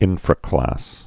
(ĭnfrə-klăs)